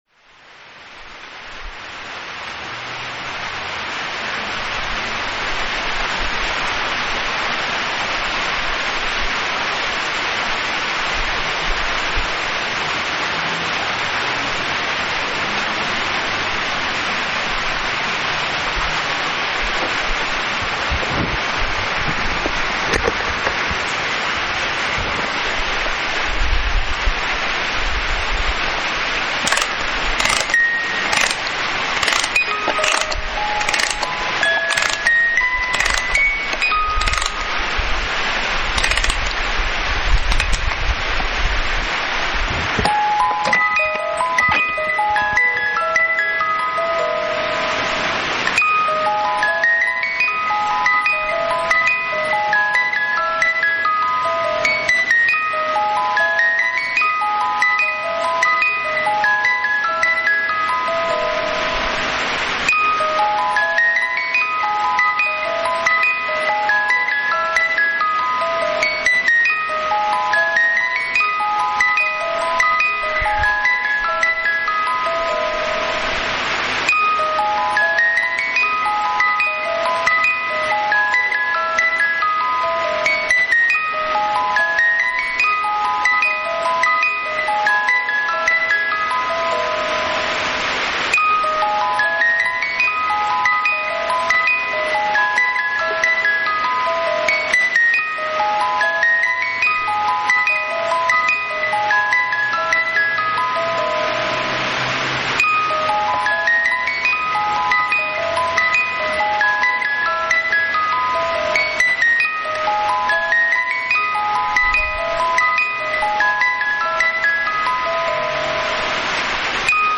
Lluvia y caja musical
El sonido de la lluvia inunda sus oídos, llegan los recuerdos que hacen que camine hacia el closet donde guarda una caja musical. Se precipita a darle cuerda y...comienza a soñar.
Equipo: Grabadora Sony ICD-UX80 Stereo